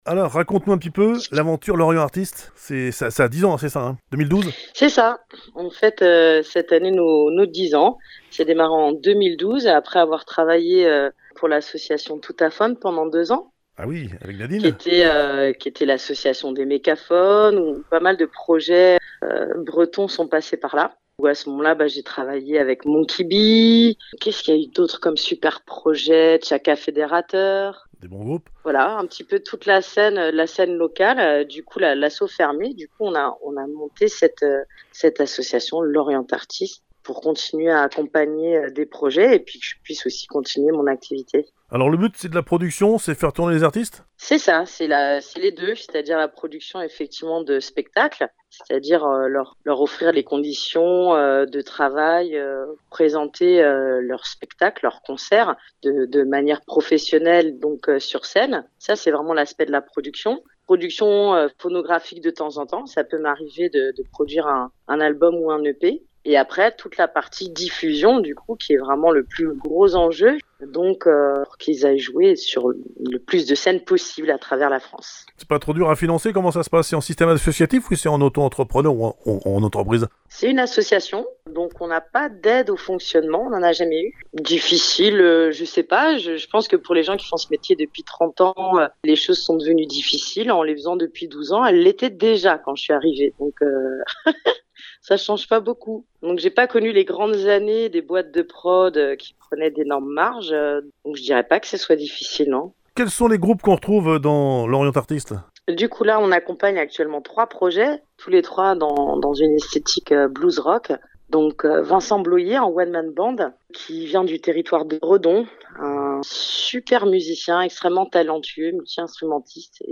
Interview
Reportage Radio Korrigans, Radio Larg, Radio Sud Belgique…